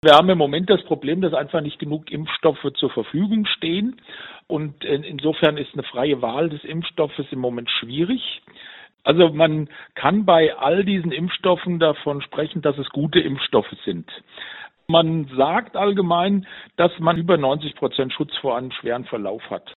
Virologe